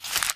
STEPS Newspaper, Sneak 01.wav